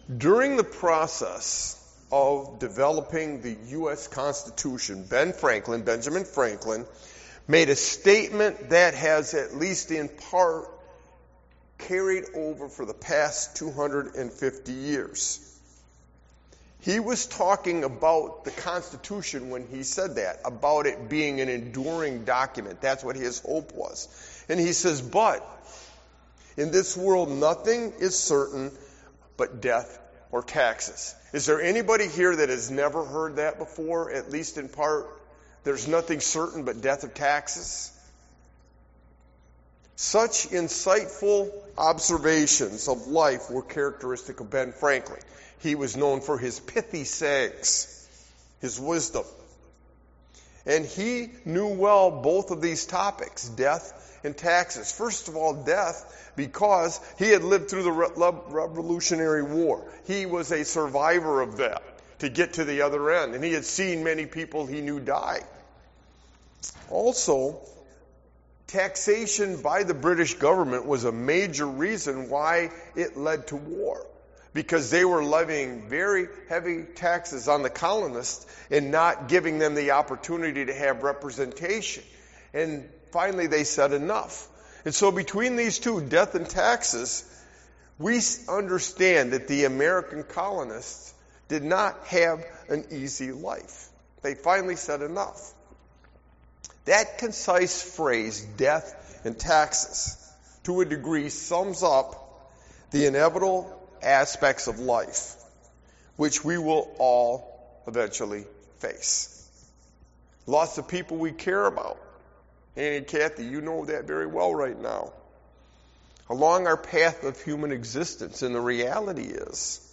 Sermon Walking with Jesus through Difficulty and Loss I